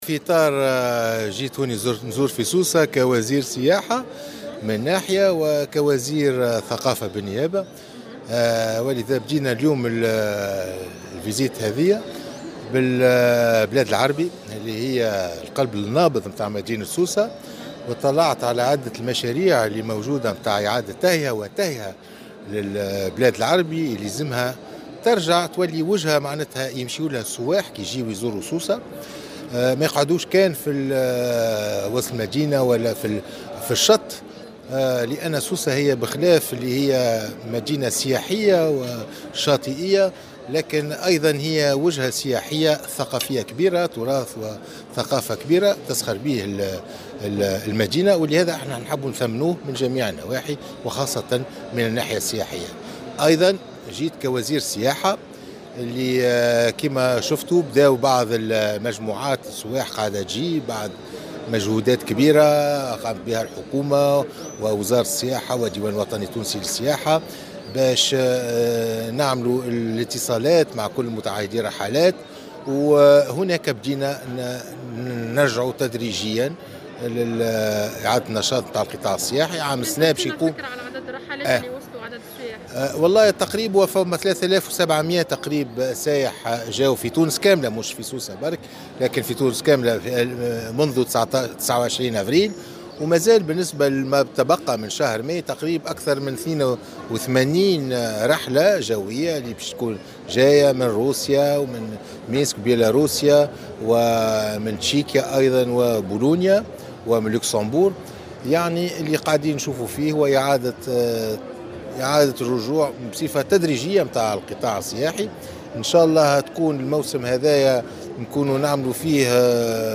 واعتبر عمار، الذي يؤدي زيارة بيومين إلى سوسة، في تصريح للجوهرة أف أم، أن هذه المؤشرات تدل على بداية القطاع السياحي في استعادة نشاطه بصفة تدريجية، بما سيتيح لأكثر من 400 ألف ناشط في القطاع استرجاع نشاطهم الذي توقف لمدة تقارب العام نتيجة جائحة كورونا.